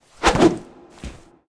Assets / Sounds / Character / Imp / mn_imp_attk_b.wav